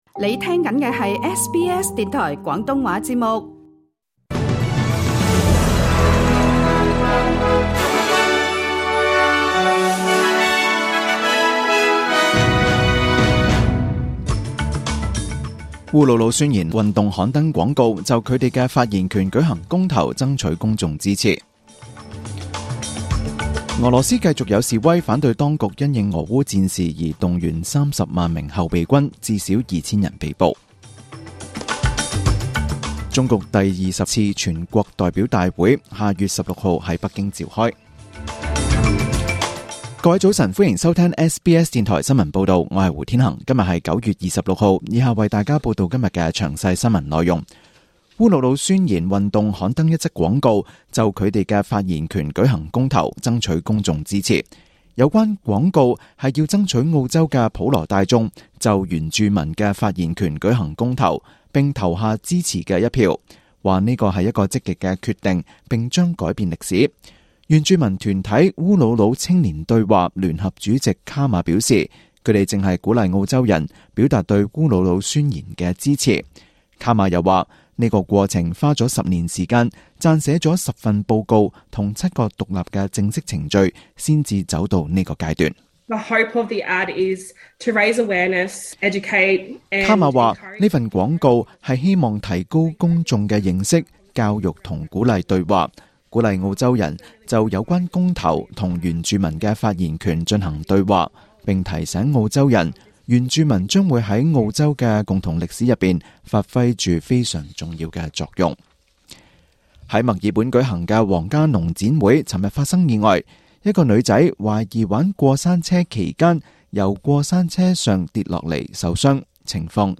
SBS廣東話節目中文新聞 Source: SBS / SBS Cantonese